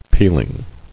[peel·ing]